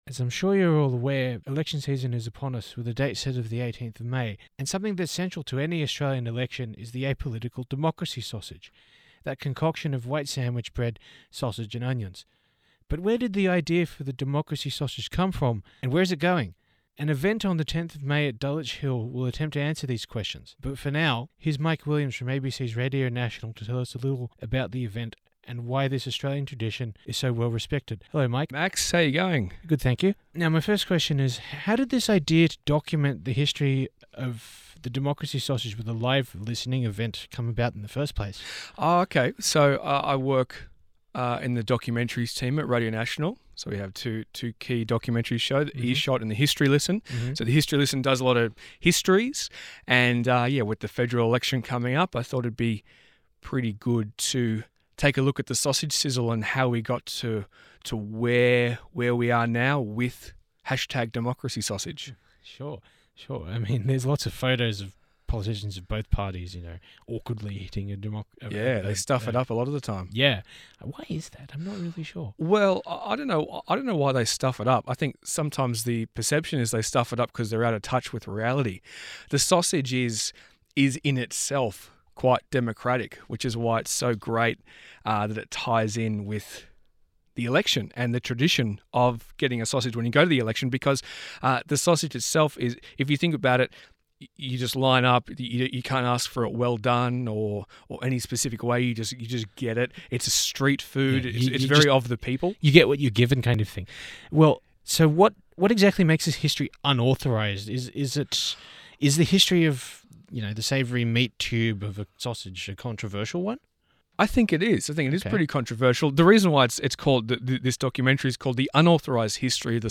Entry by donation to Lions Australia. 2ser interview about the sausage listening event https